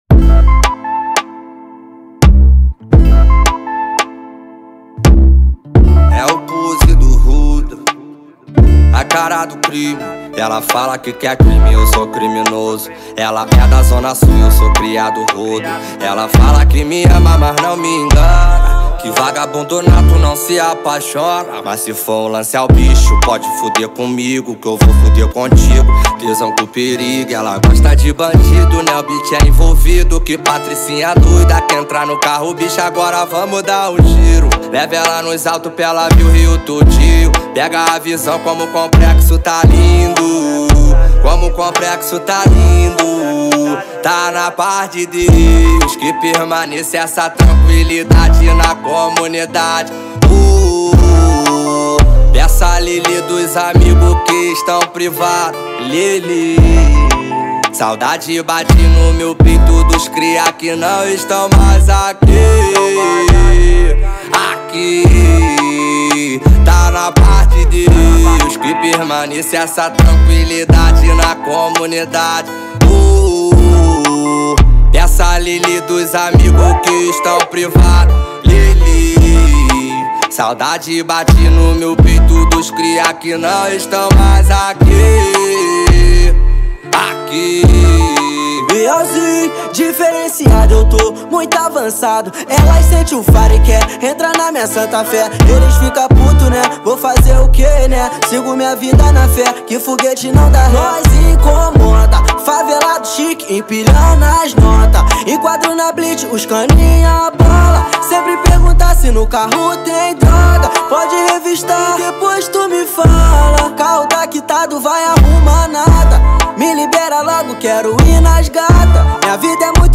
Gênero: Funk